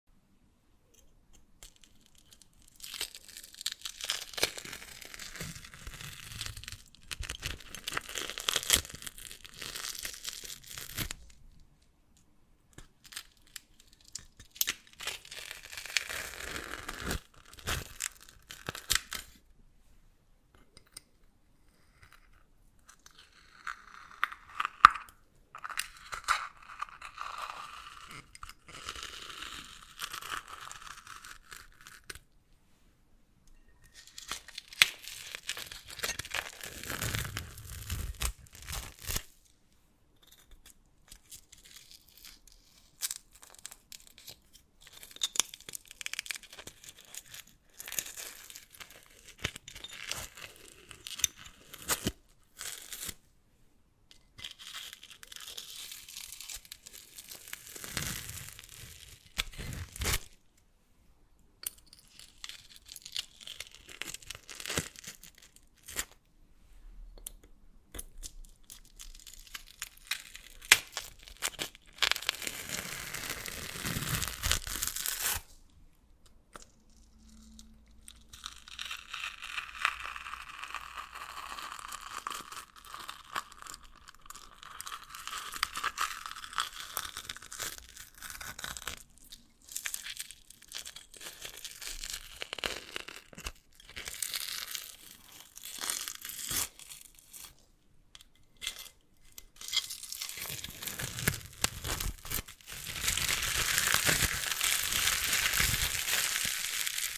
ASMR Peeling Off The Film Sound Effects Free Download